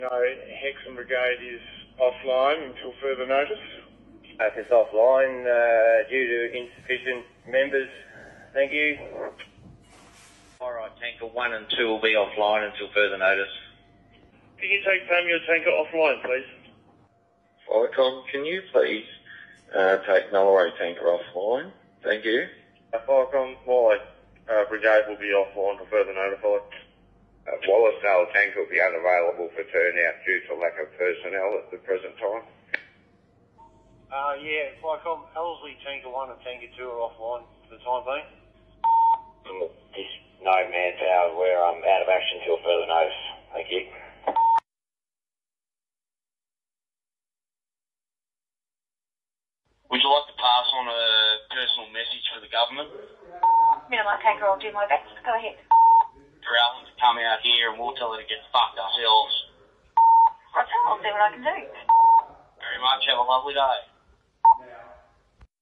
Recording’s of CFA fire fighters protesting against the Emergency Services Levy